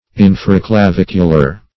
Search Result for " infraclavicular" : The Collaborative International Dictionary of English v.0.48: Infraclavicular \In`fra*cla*vic"u*lar\, a. [Infra + clavicular.]
infraclavicular.mp3